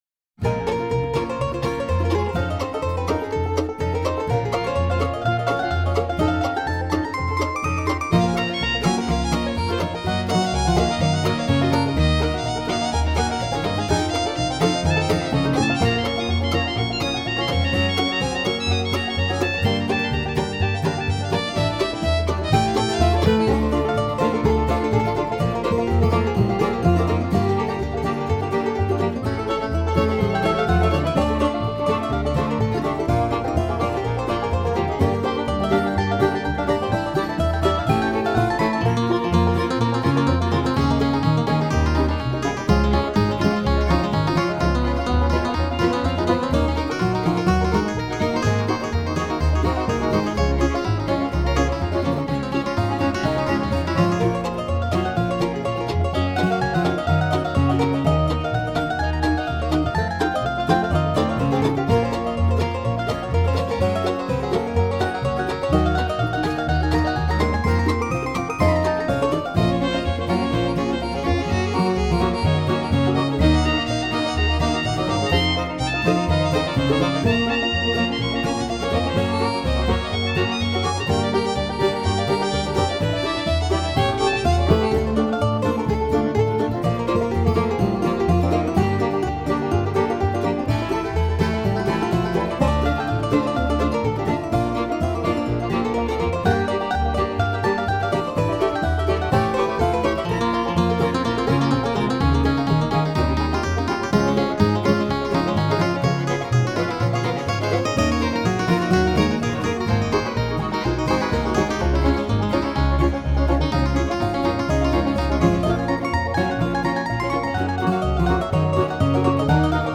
カントリー
で、今回は、カントリー音楽の１ジャンル、ブルーグラスに挑戦してみました。
「ベース､バンジョー､マンドリン､ギター､フィドルで演奏するブルーグラス。本格的なブルーグラスらしくドラムなし｡
何とか、ブルーグラスのニュアンスは伝わるような曲になったかな、と思います。